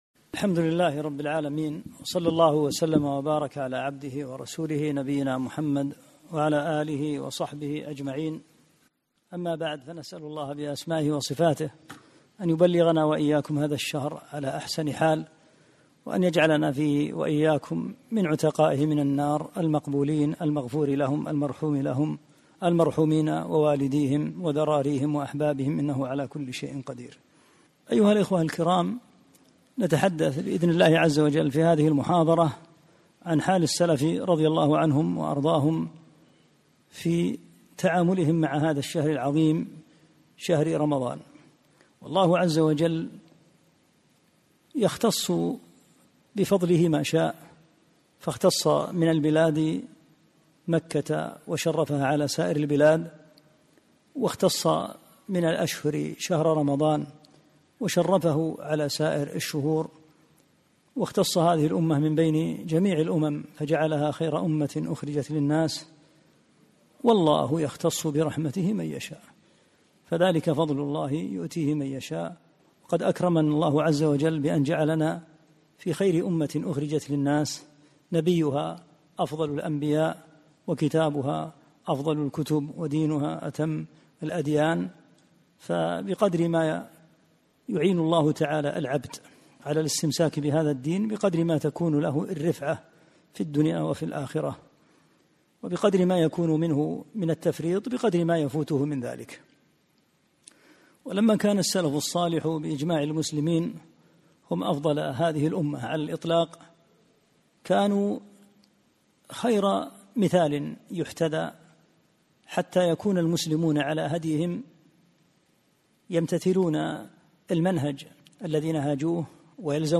محاضرة - حال السلف في استقبال رمضان